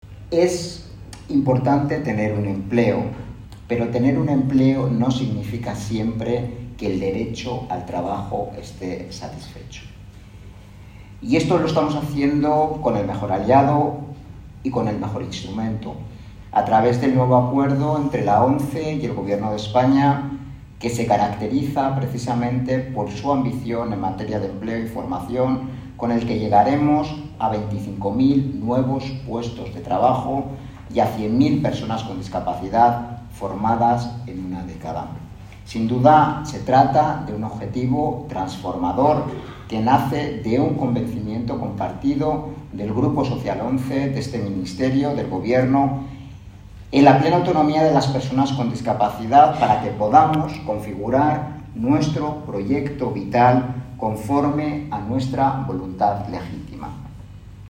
Embajadores, cónsules y consejeros de embajadas participaron en la sede ‘Por Talento Digital’, de Fundación ONCE, en el VII Encuentro Diplomacia para la Inclusión organizado por el Grupo Social ONCE y la Academia de la Diplomacia, bajo el patrocinio del embajador de la República Checa en nuestro país, que ostenta la presidencia semestral del Consejo de la UE.